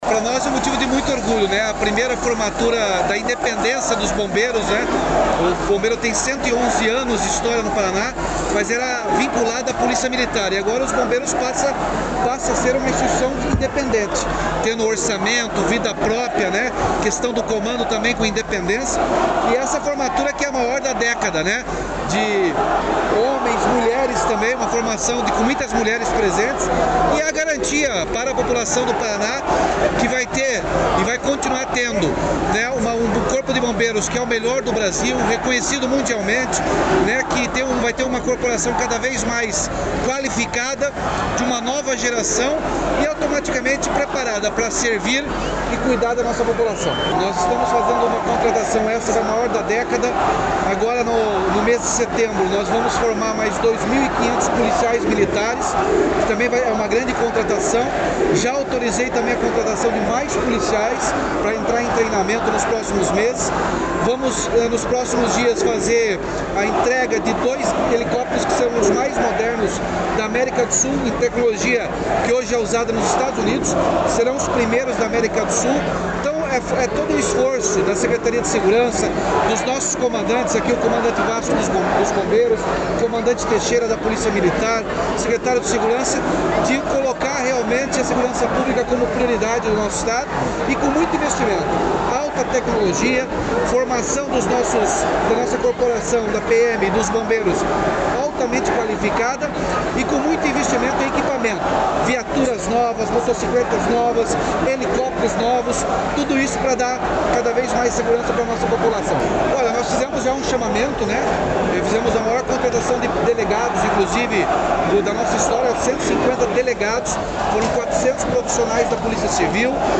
Sonora do governador Ratinho Junior sobre a contratação de 419 bombeiros militares, a maior da década no Paraná
RATINHO JUNIOR - FORMATURA BOMBEIROS.mp3